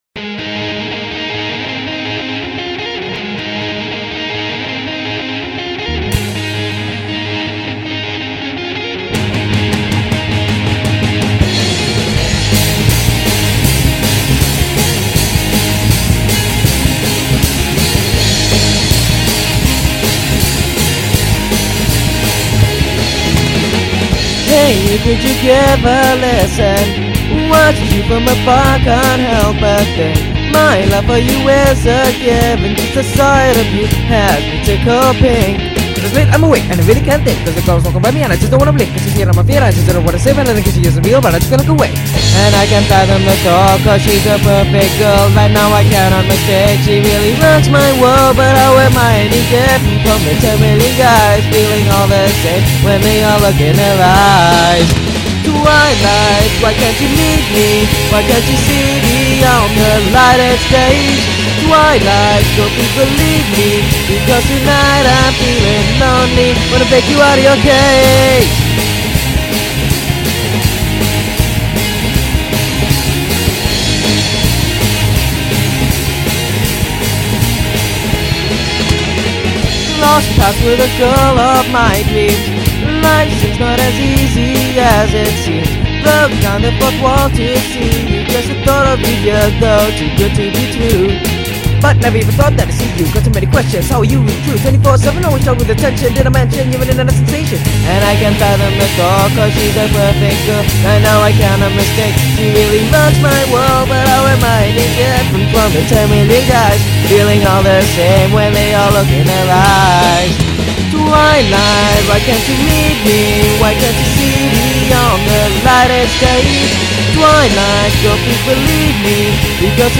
Guitars/bass/vocals/drums
Setting the mics and studio up